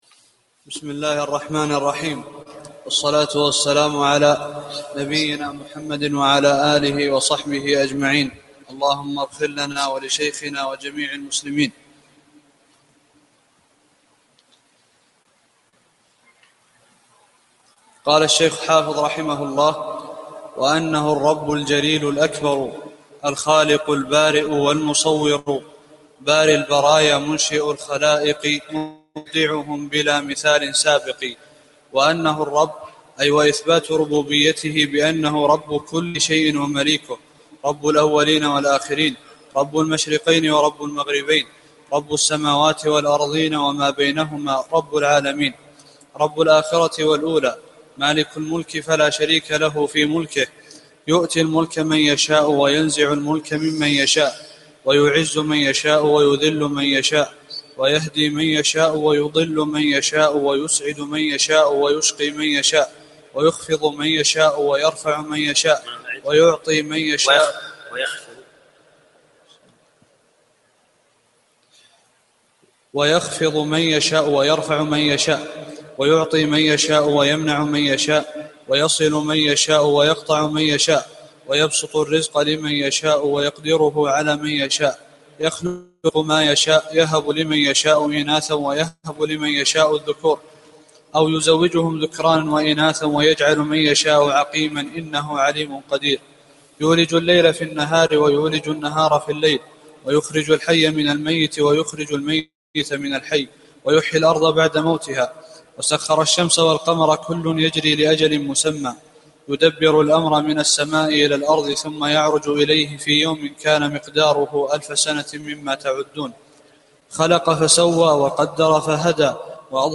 12 - الدرس الثاني عشر